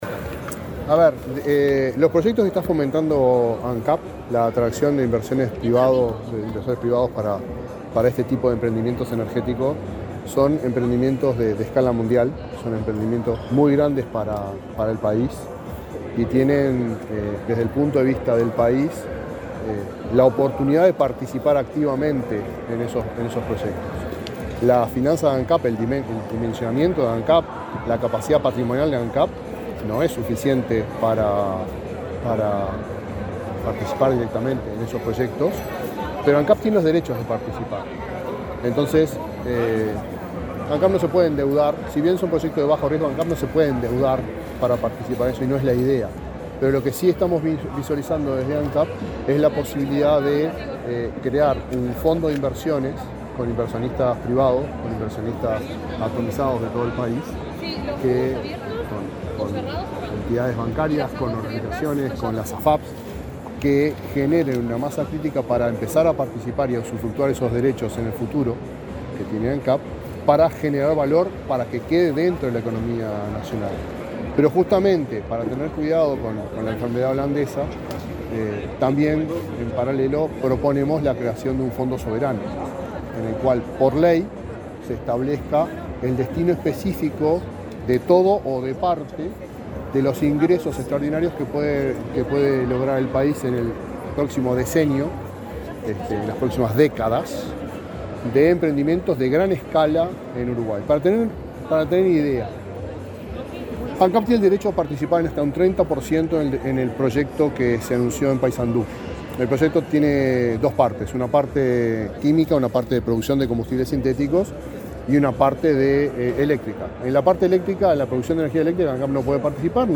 Declaraciones del presidente de Ancap a la prensa
Declaraciones del presidente de Ancap a la prensa 23/08/2023 Compartir Facebook X Copiar enlace WhatsApp LinkedIn El presidente de Ancap, Alejandro Stipanicic, disertó en un desayuno de trabajo de Somos Uruguay, realizado este miércoles 23 en el Aeropuerto de Carrasco. Luego, dialogó con la prensa.